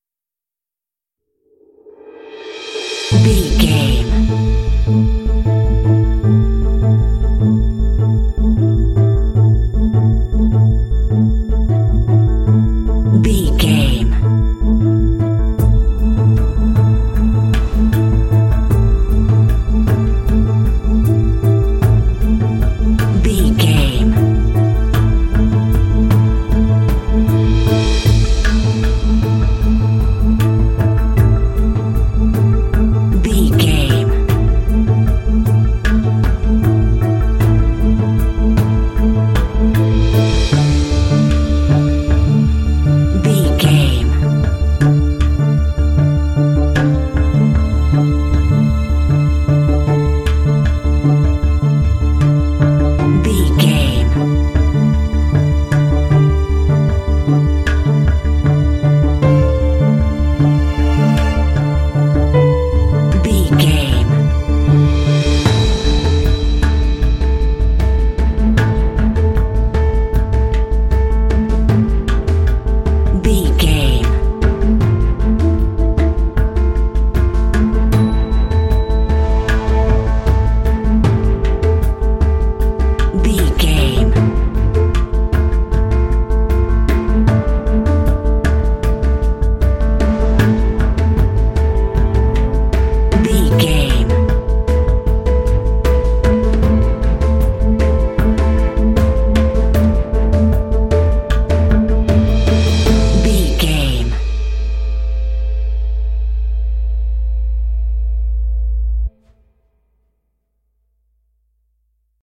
Aeolian/Minor
tension
suspense
dramatic
contemplative
drums
piano
strings
synthesiser
cinematic
film score